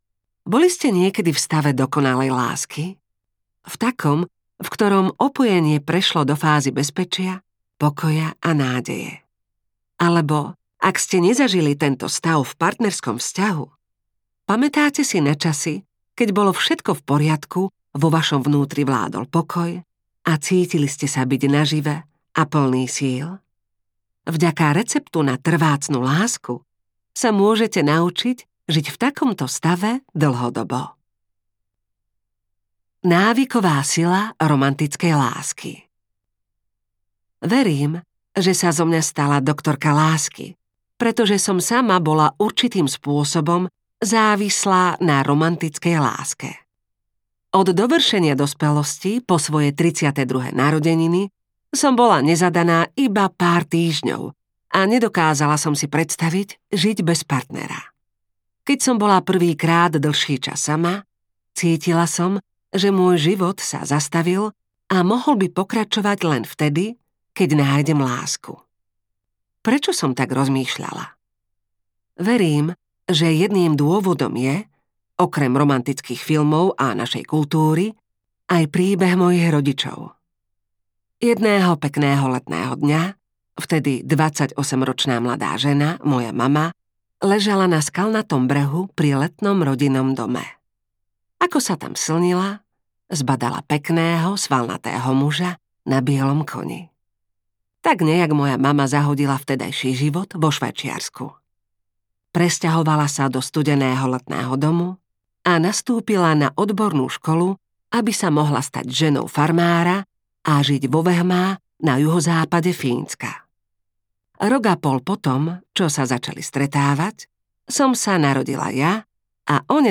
Hormóny lásky audiokniha
Ukázka z knihy